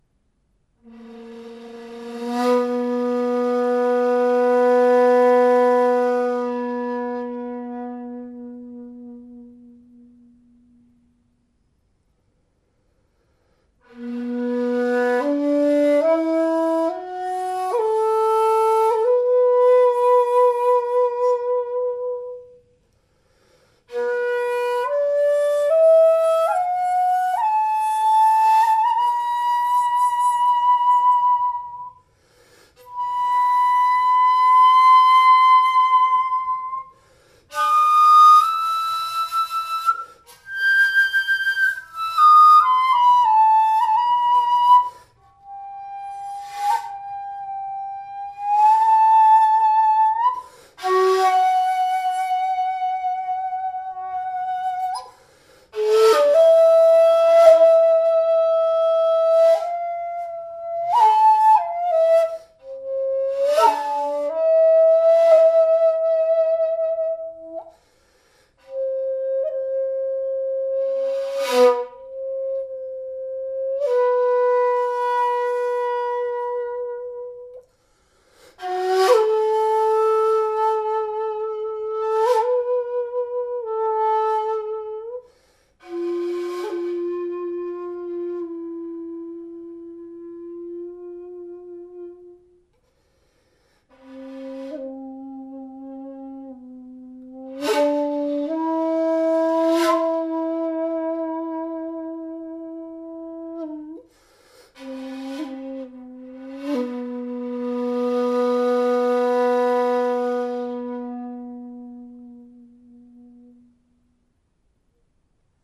Shakuhachi Ji-ari